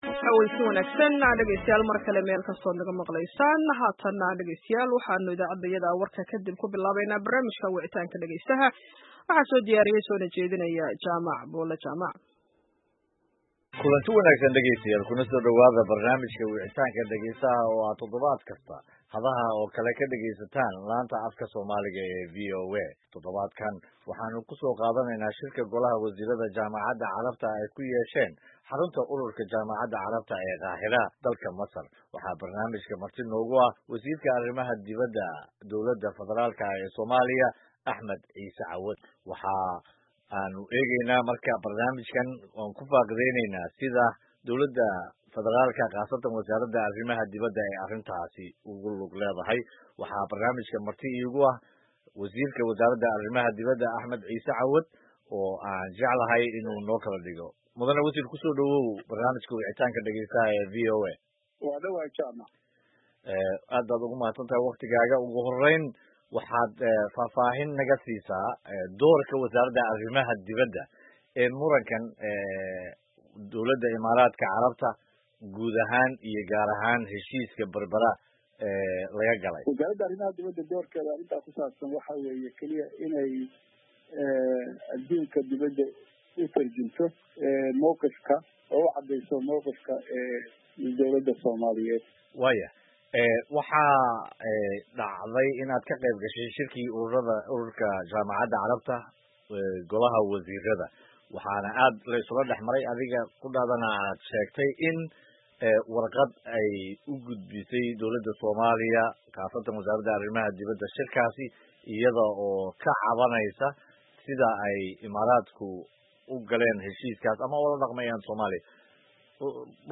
Barnaamijka Wicitaanka Dhageystaha iyo Wasiirka Arr. Dibadda
Barnaamijka Wicitaanka waxaa maanta marti ku ah Wasiirka Arrimaha Dibadda ee Somalia, Axmed Ciise Cawad oo ka hadlaya heshiiska Berbera, qodobkaas oo aan isna ka wareysanaya Wasiirka Arrimaha Dibadda Somaliland, Sacad Cali Shire.